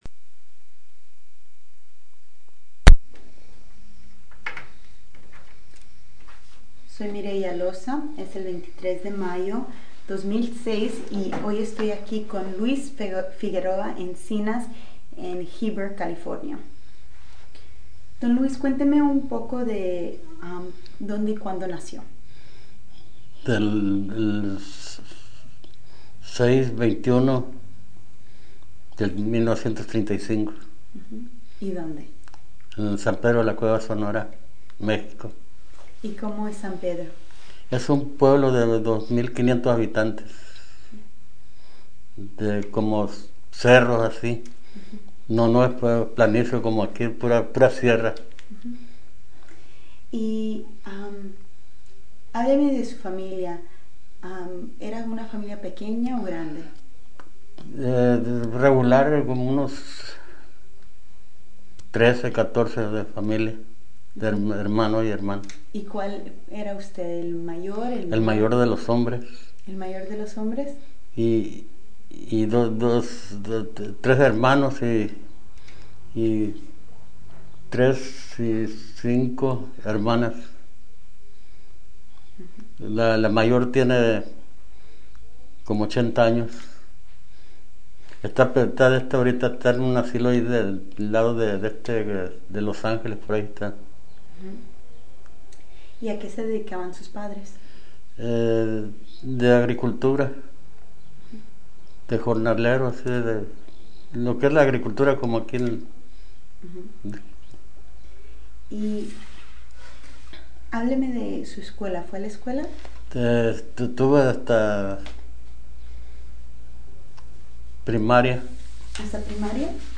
Location Heber, CA Original Format Mini disc